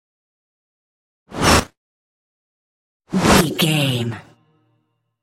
Flying logo whoosh x2
Sound Effects
futuristic
intense
whoosh